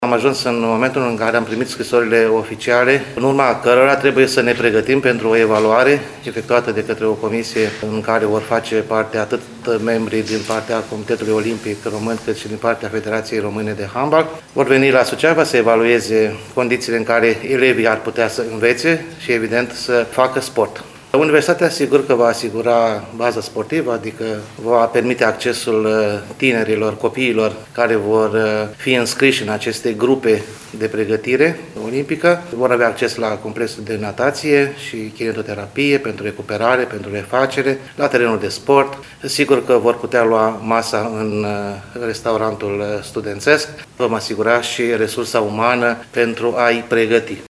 Rectorul VALENTIN POPA a precizat că Universitatea Suceava și-a exprimat întreg sprijinul pentru înființarea Centrului național olimpic de pregătire a juniorilor la handbal masculin.